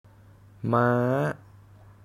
Tone: starts mid, rises high
ToneMidLowFallingHighRising
Phoneticmaamàamâamáamǎa